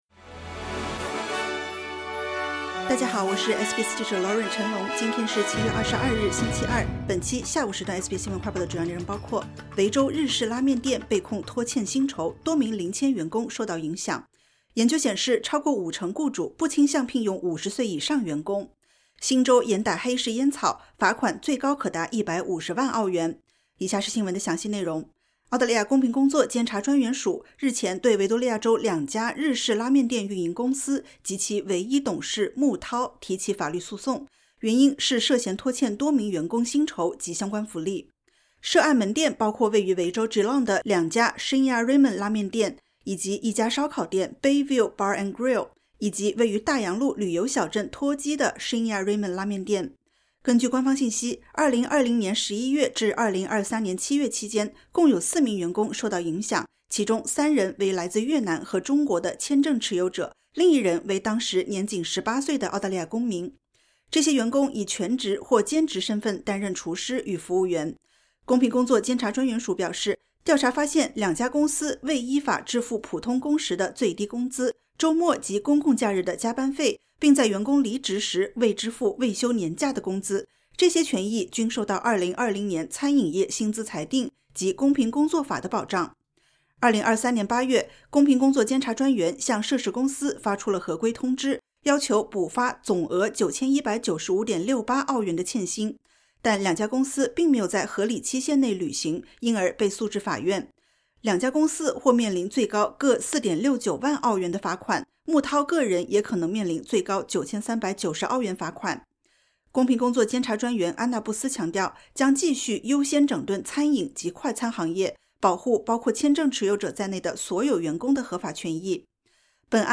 【SBS新闻快报】维州连锁拉面店因涉嫌拖欠薪酬被起诉